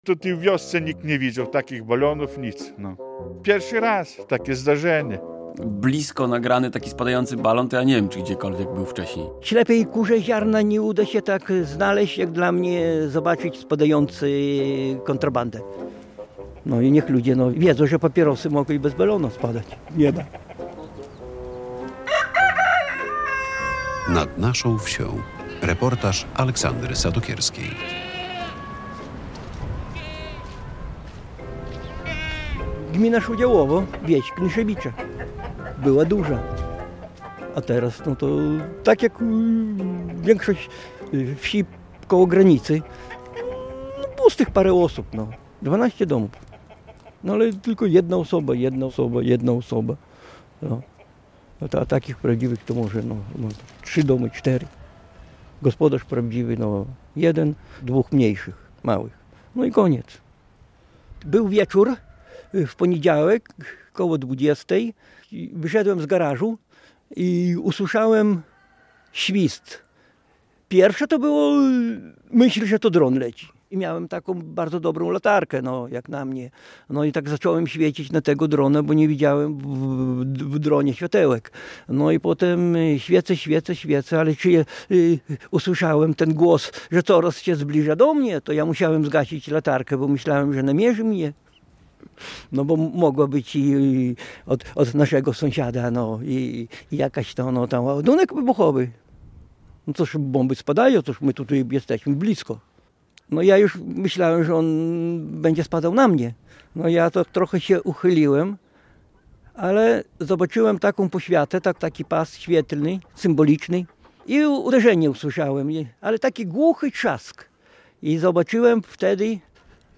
Radio Białystok | Reportaż | "Nad naszą wsią..."